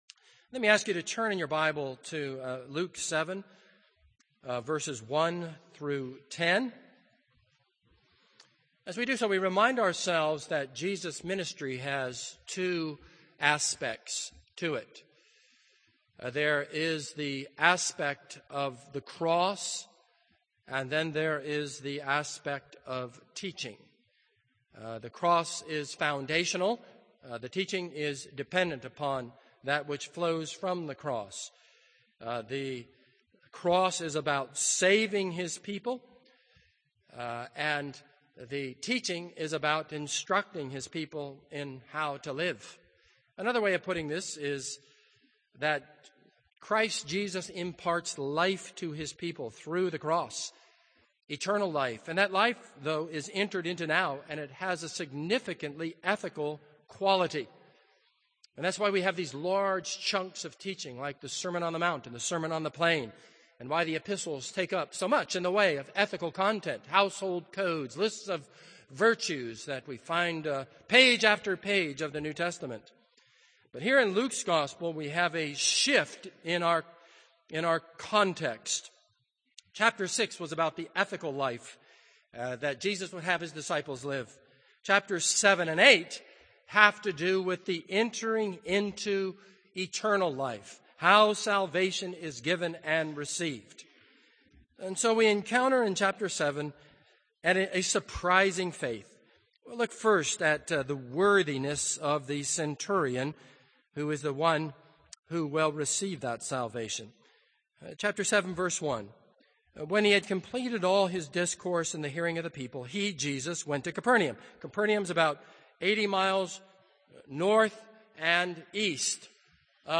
This is a sermon on Luke 7:1-10.